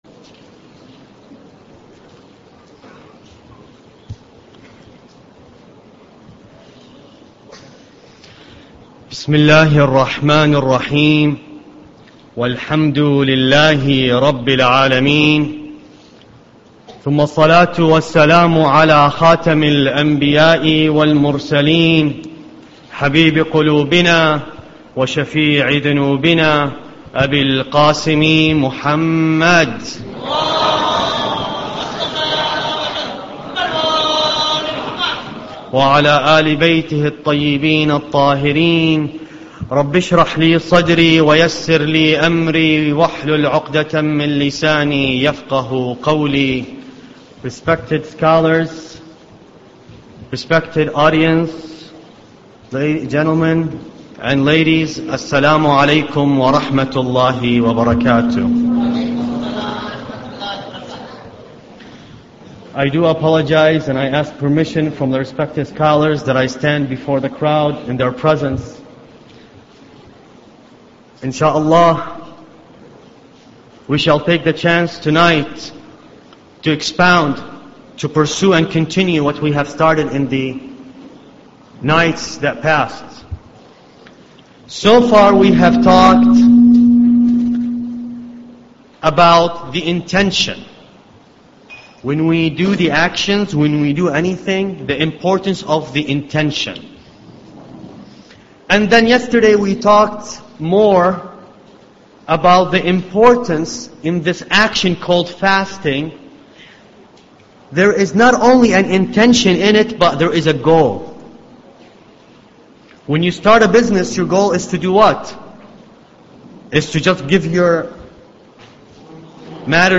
Ramadan Lecture 3